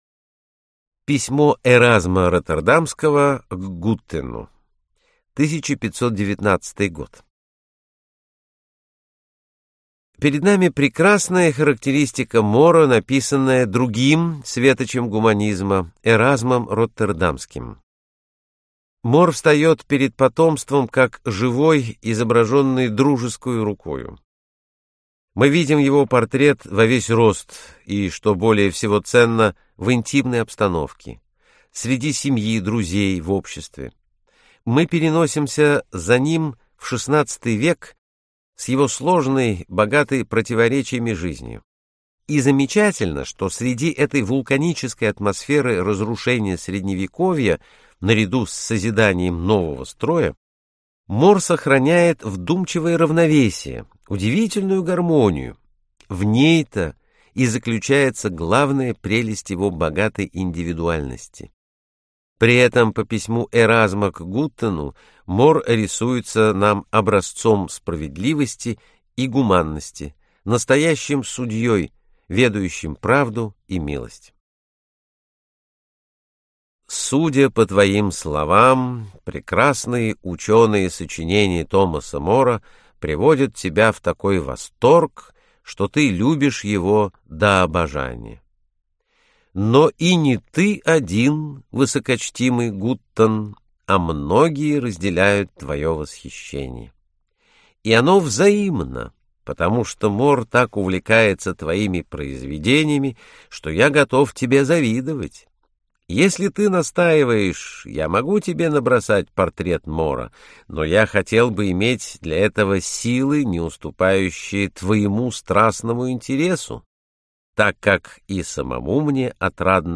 Аудиокнига Утопия | Библиотека аудиокниг